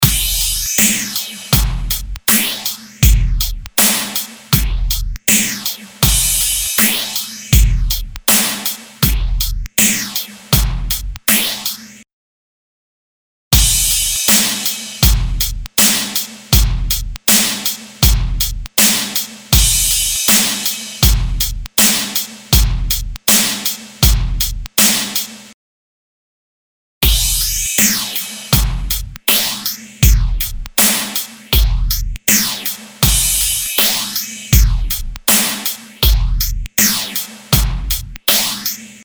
Instant Phaser Mk II | Drums | Preset: Cashmere
世界初のスタジオ・フェイザー
Instant-Phaser-Mk-II-Eventide-Drum-Set-Cashmere.mp3